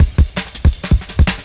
Cymbal Rise Cut 1.wav